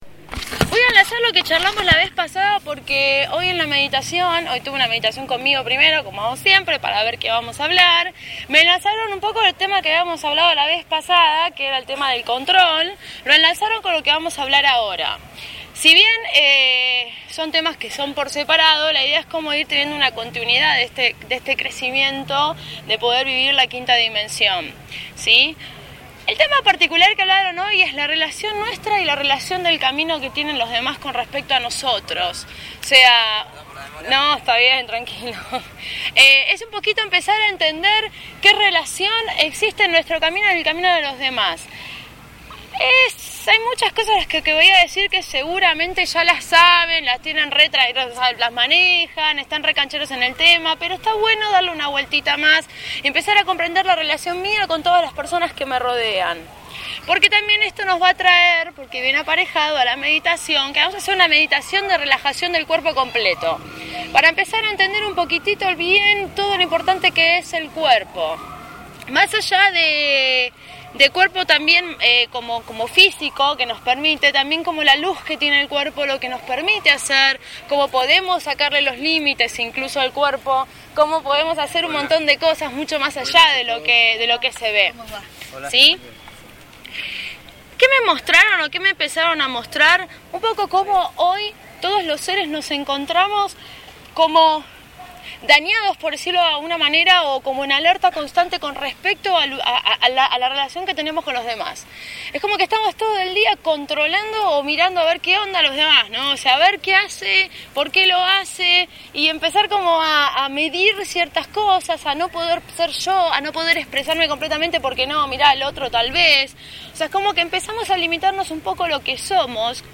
Charla gratuita en Buenos Aires – Tema: Nuestra relación con los demás seres
En Plaza Irlanda, Capital Federal, Argentina.